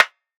DDW2 PERC 3.wav